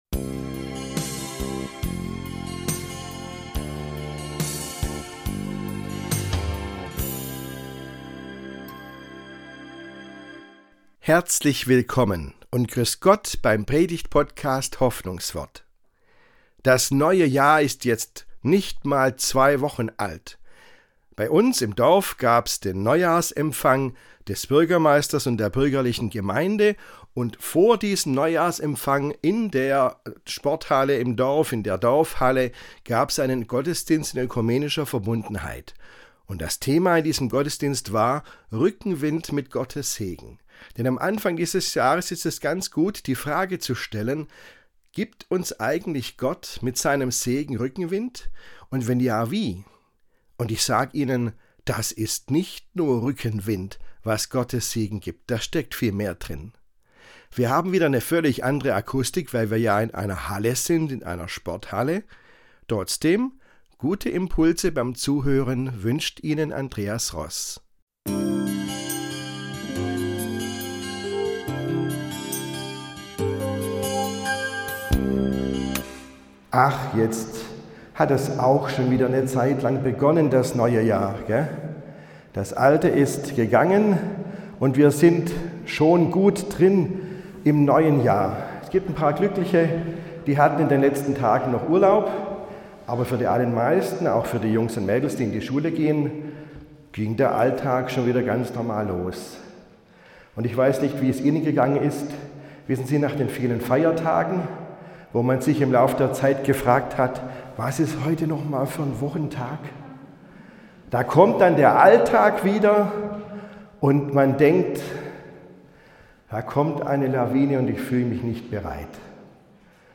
(Aufnahme aus der Dorfhalle, 11.1.2026) Mehr